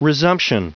Prononciation du mot resumption en anglais (fichier audio)
Prononciation du mot : resumption